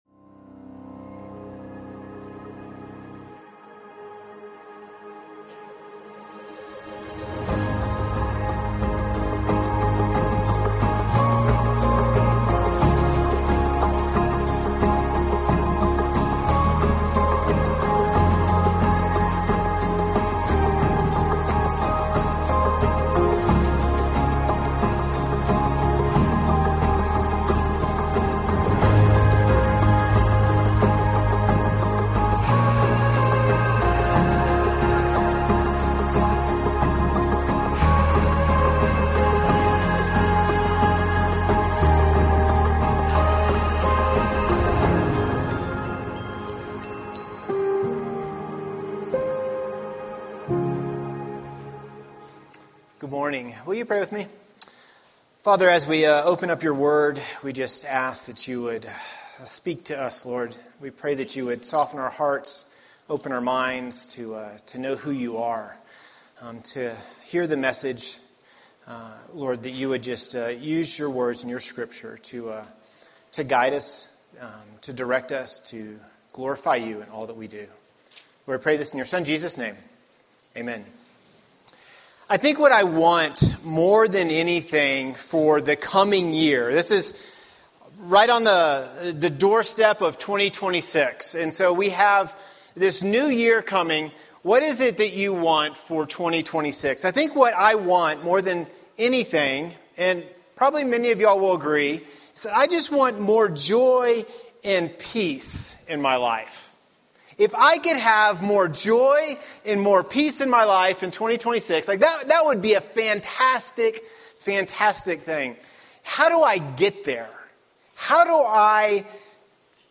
A collection of ACC Sunday Messages that are not a part of a Sermon Series